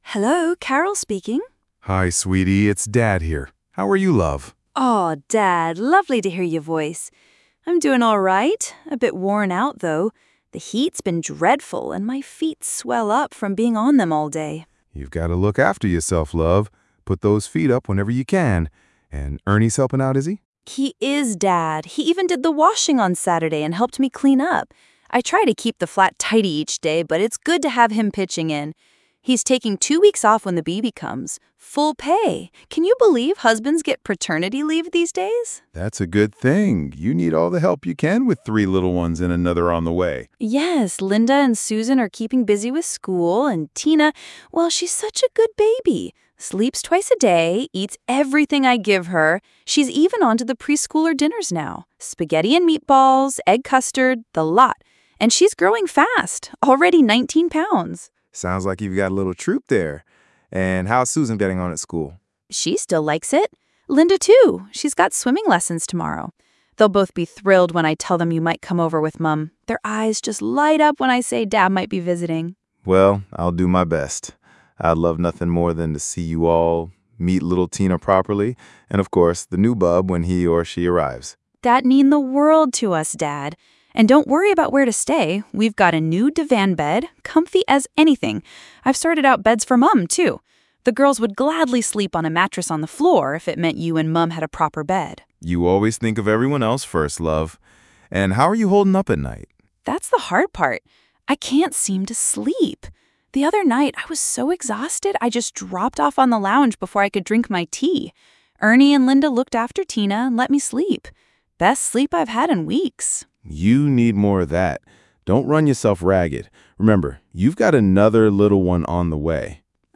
Radio play